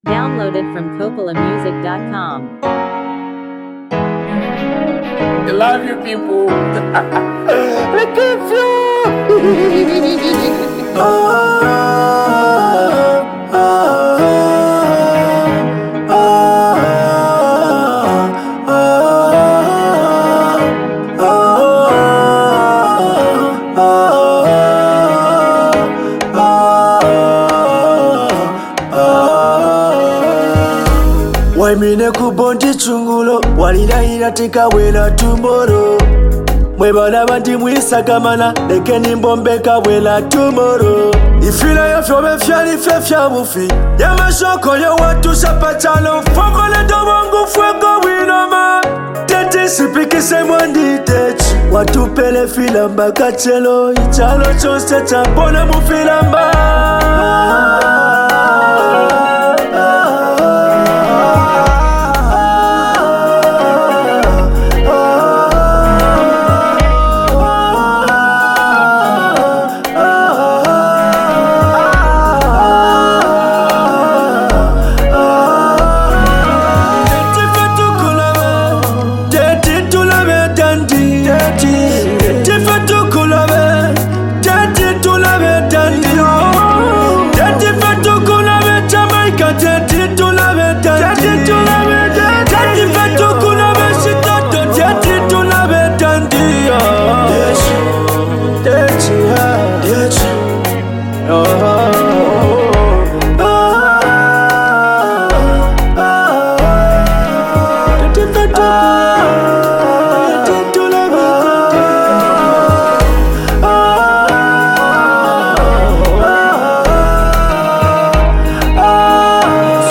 delivery is sincere and heavy with emotion